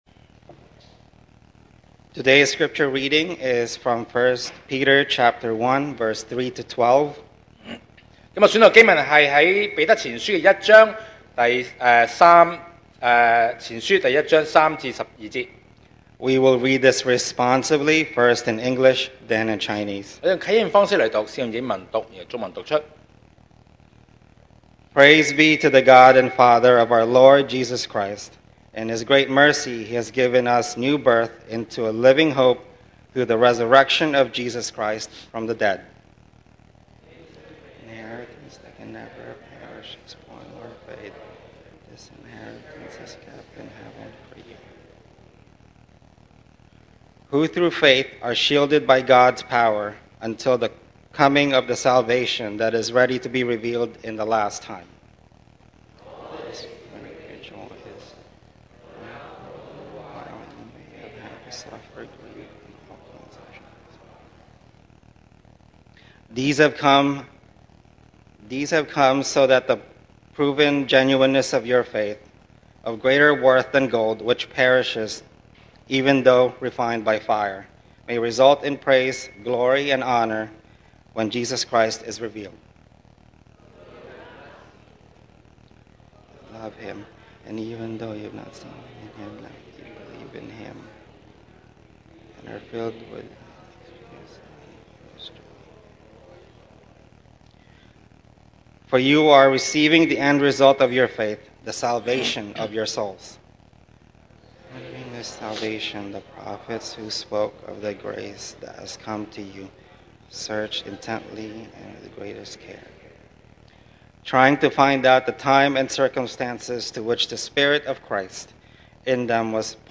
2024 sermon audios
Service Type: Sunday Morning